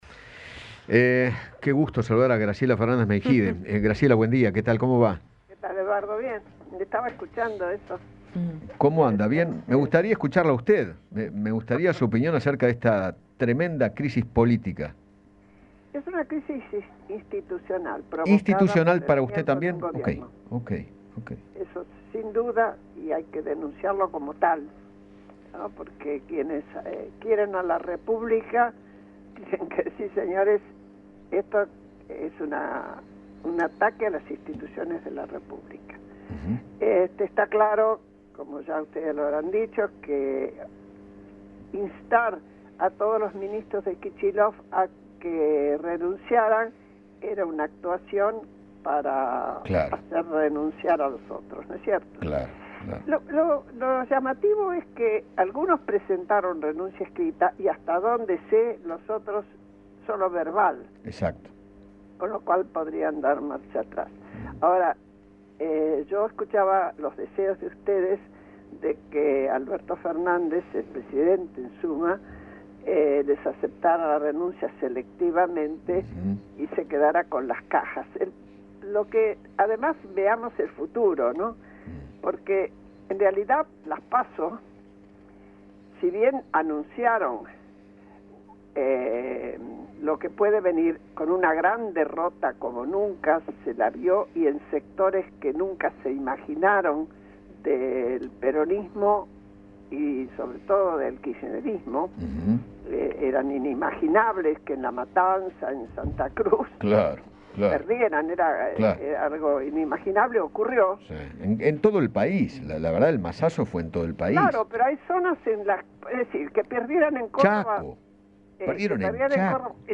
Graciela Fernández Meijide, política y activista de derechos humanos, conversó con Eduardo Feinmann sobre la renuncias de ministros y funcionarios del Gobierno y aseguró que “las PASO anunciaron lo que puede venir con una gran derrota y en sectores que nunca imaginó el kirchnerismo”.